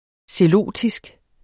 Udtale [ seˈloˀdisg ]